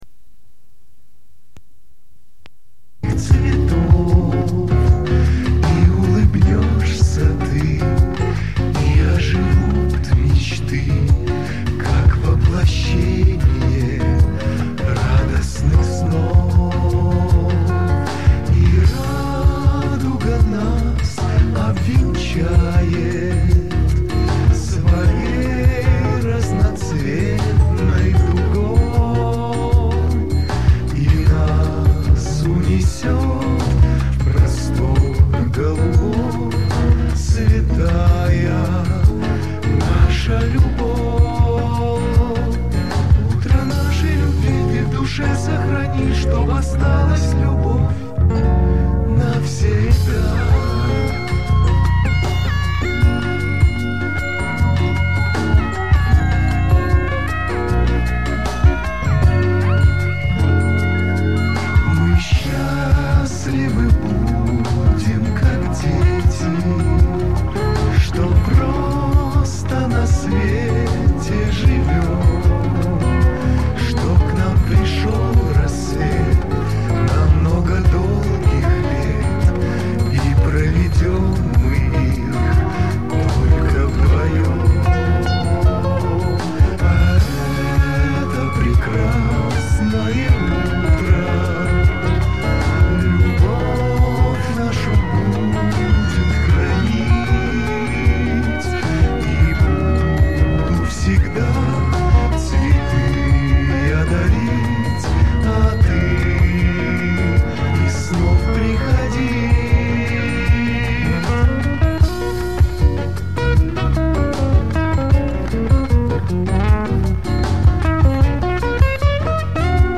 ЗАПИСЬ С КАНАЛА "МЕЛОДИЯ"